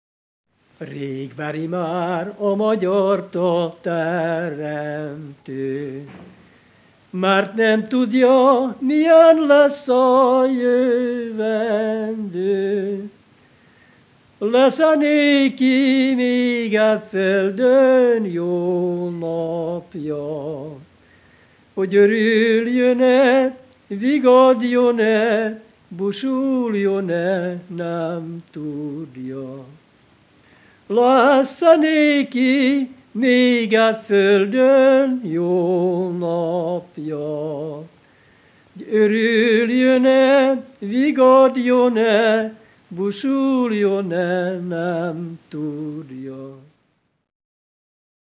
Multipart Music, Instrumentation of Sound, Instrumentalization of Sound, Sound and Society, Performance as Instrumentation, Tradition, Revival
Folk & traditional music